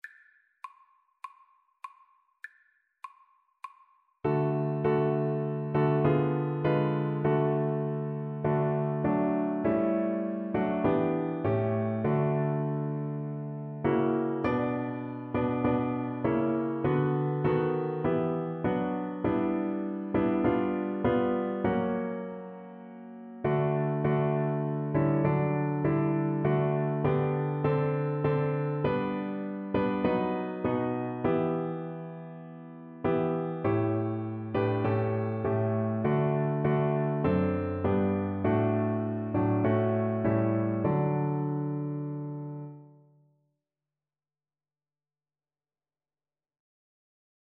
4/4 (View more 4/4 Music)